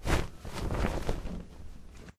rustle1.ogg